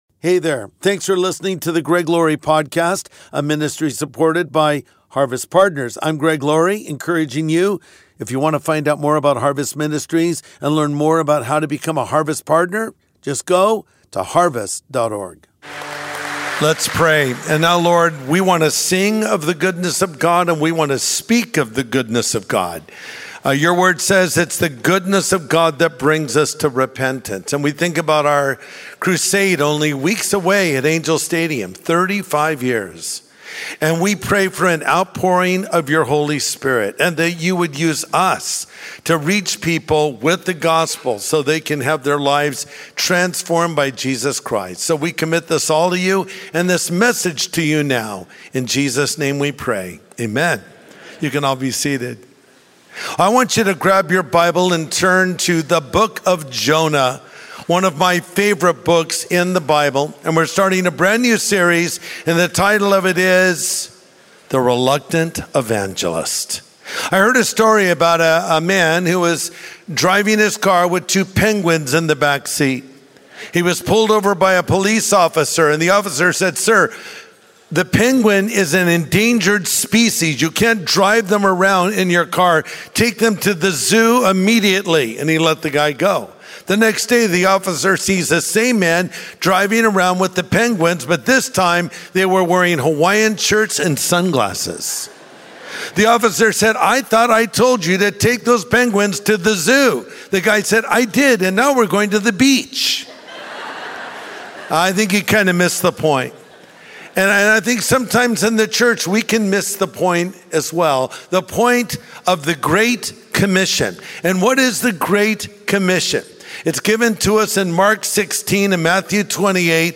The Reluctant Evangelist | Sunday Message